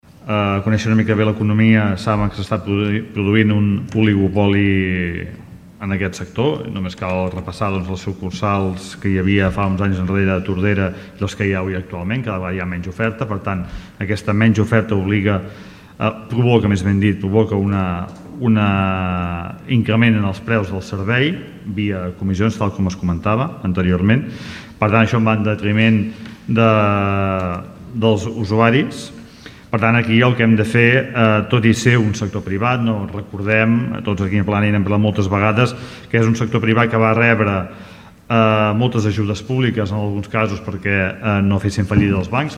El Ple de l’Ajuntament de Tordera va aprovar una moció per controlar la digitalització dels serveis bancaris.
Des de +Tordera, Xavier Pla avisa que el servei bancari es dirigeix cap a un oligopoli i que, malgrat ser del sector privat, cal controlar-ho.